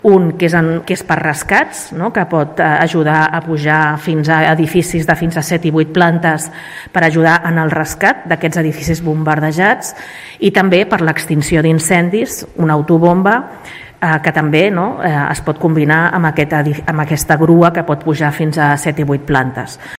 Ada Colau explica los dos vehículos que se enviaran a Kyev los próximos días.